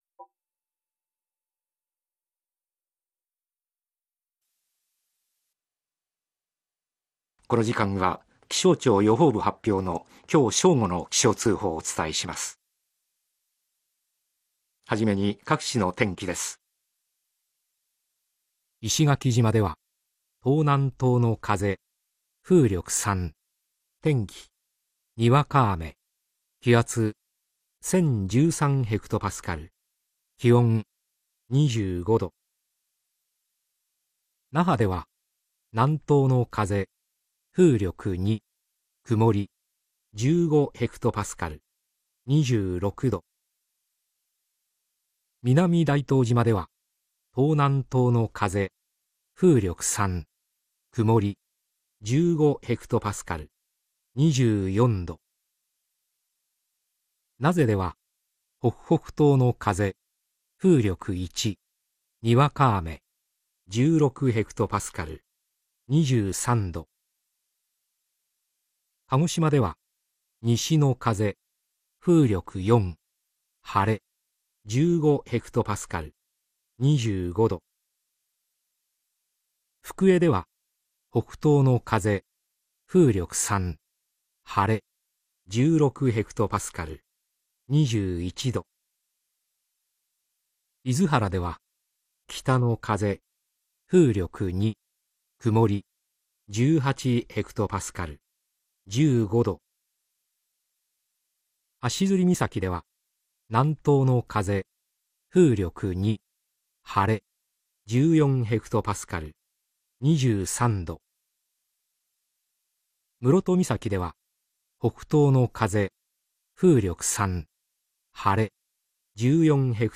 気象情報論 目次 １．天気図 1.1. 地上天気図 平成31年4月19日12時気象通報サンプル H31.4.19天気図 1.2. 高層天気図 NOAA高層天気図サイト 2.